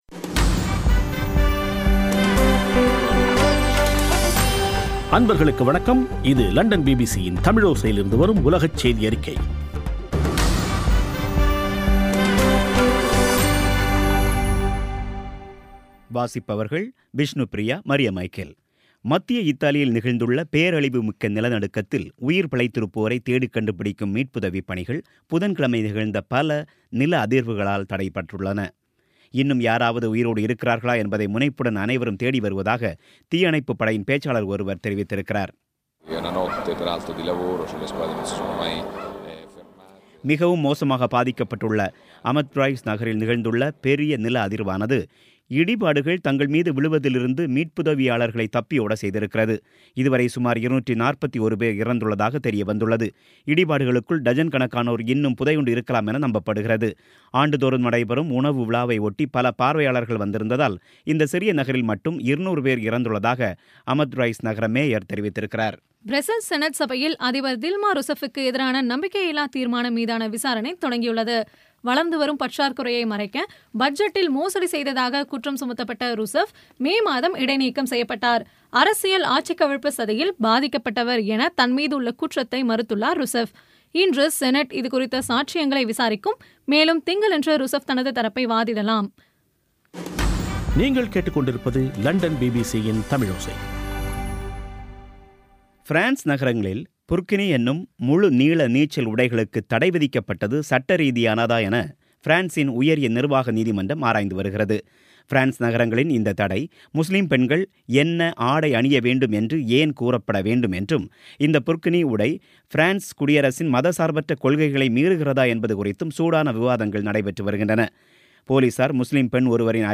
பிபிசி தமிழோசை செய்தியறிக்கை (25/08/16)